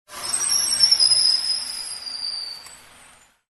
Свист тормозов автобуса при остановке